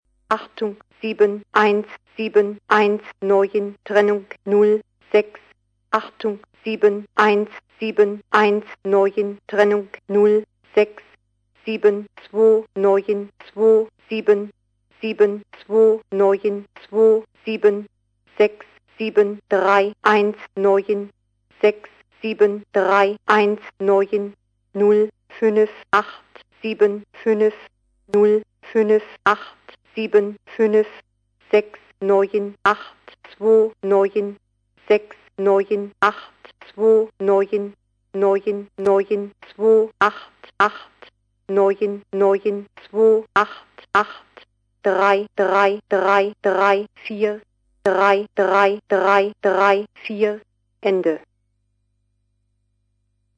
Enkele opnames van nummerstations
Oost-Duitse 'Stimme' Gesynthetiseerd stem van, Oost-Duitse geheime dienst.